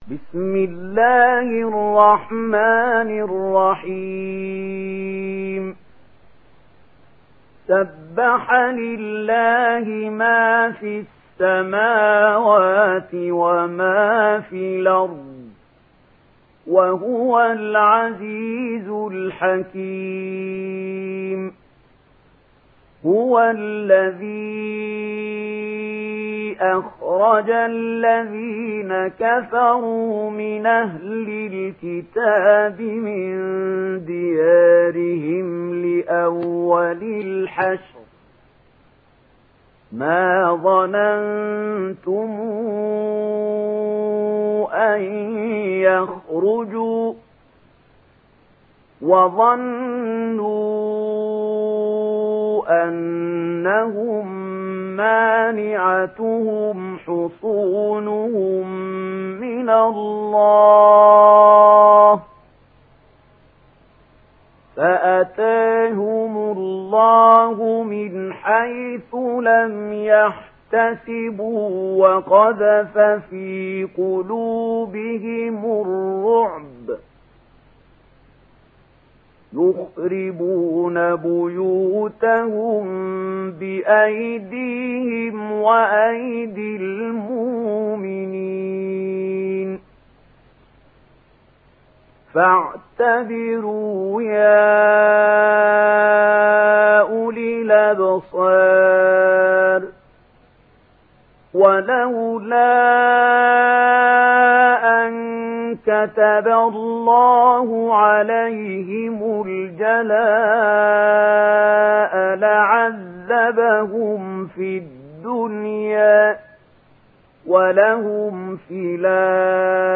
دانلود سوره الحشر mp3 محمود خليل الحصري (روایت ورش)